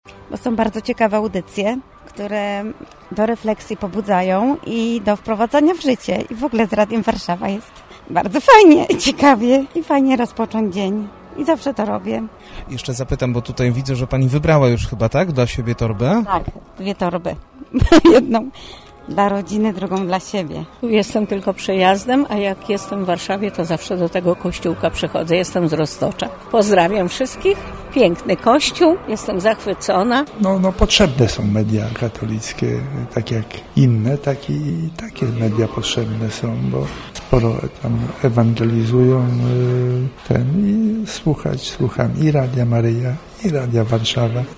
Kolejne spotkanie ze słuchaczami było okazją do rozmów z naszymi wolontariuszami i reporterem.
sluchacze.mp3